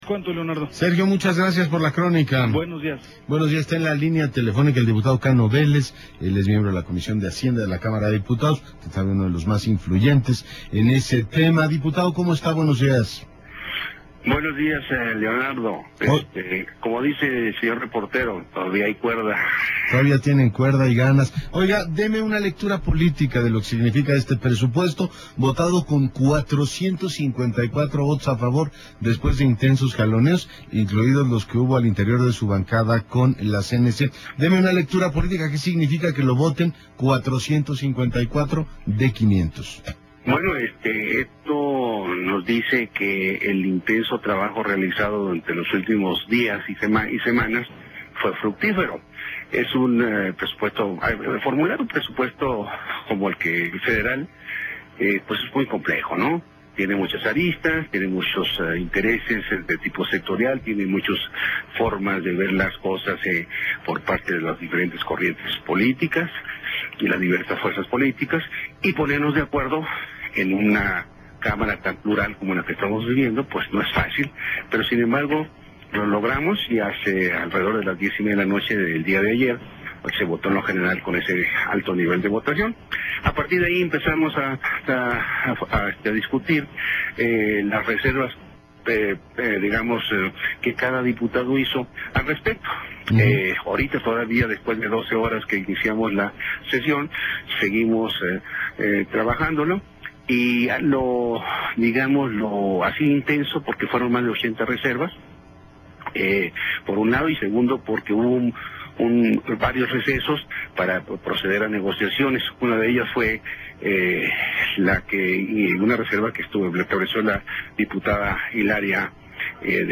16-11-10 Entrevista de Radio con Leonardo Curzio